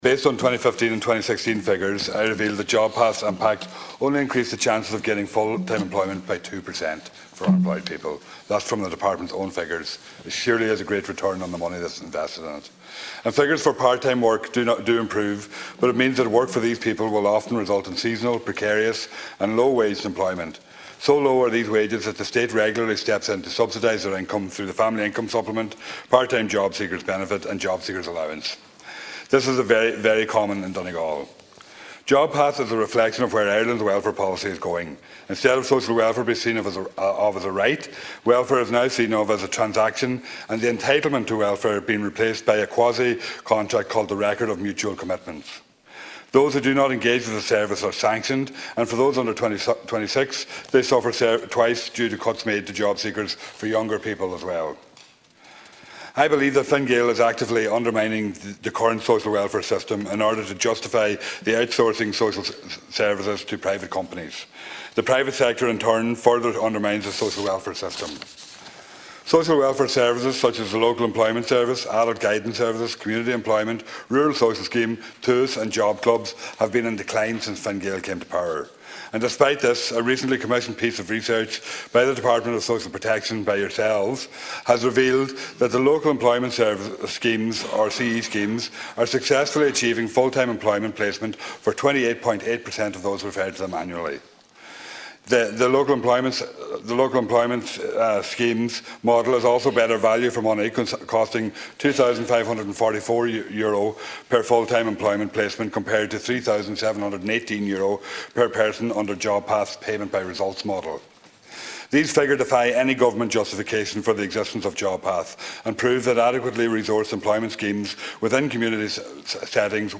Speaking in the Dail in recent days, Deputy Pringle says the issue is prevalent in Donegal and the scheme, he says, is simply not working: